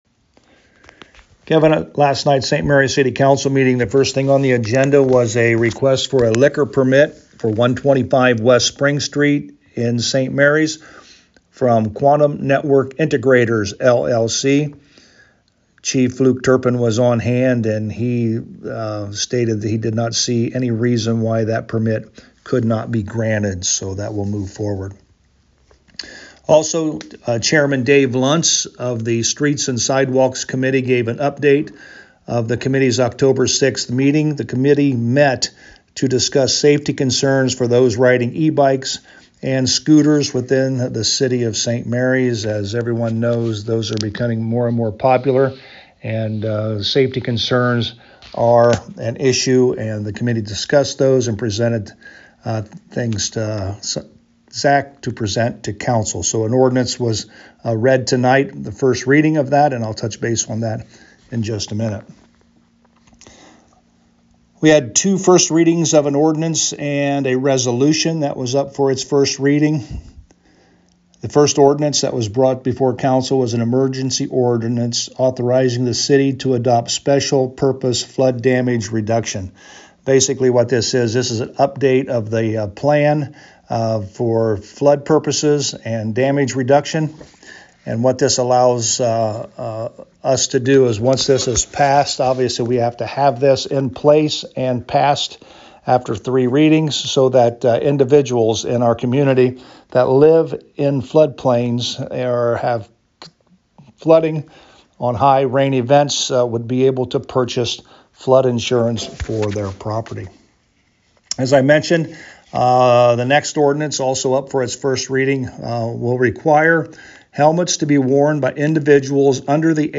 To hear a summary with Mayor Joe Hurlburt: